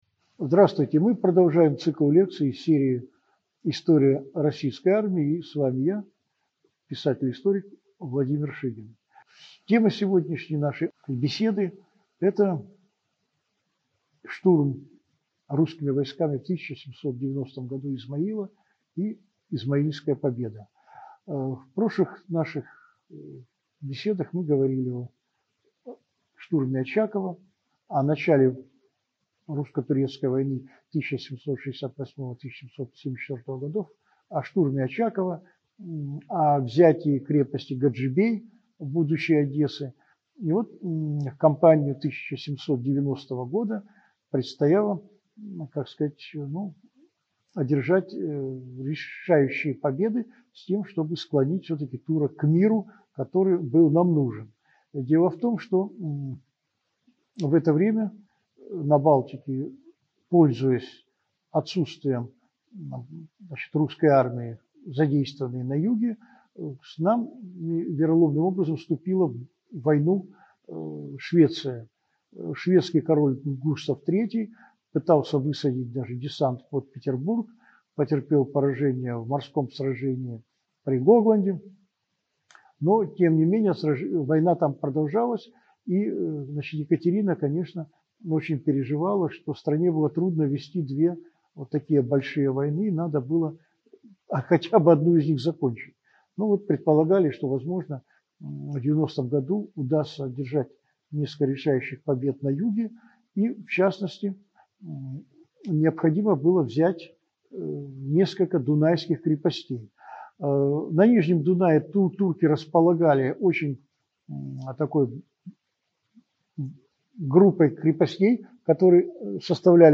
Аудиокнига Штурм Измаила | Библиотека аудиокниг